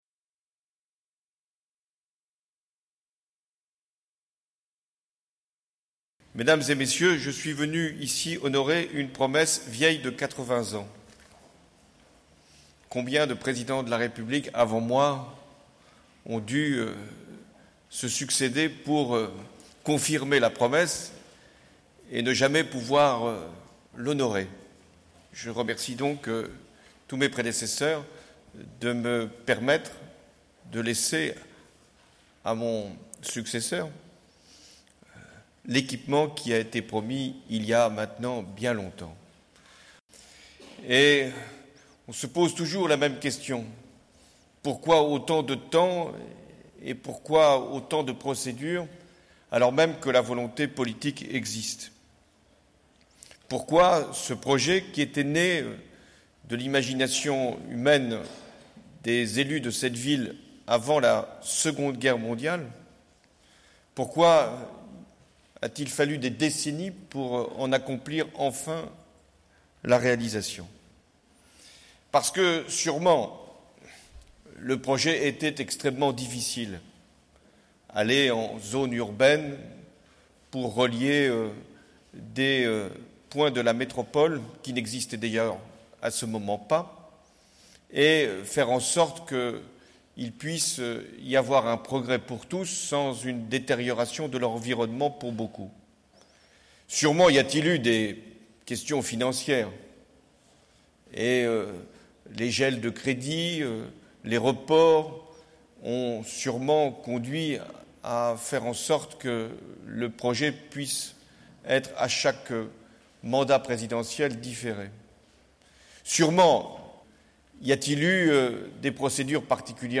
François Hollande a inauguré la L2 ce jeudi 8 décembre entouré de Martine Vassal, Jean-Claude Gaudin et Christian Estrosi (Photo Elysée)